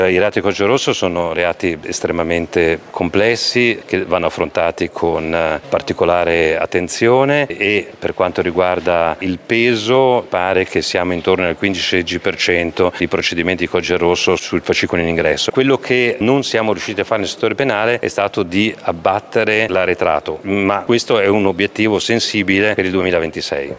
Più lenti invece i tempi della giustizia penale dovuti anche all’aumento dei reati per codice rosso che hanno precedenza assoluta, sentiamo ancora il presidente del Tribunale Alberto Rizzo…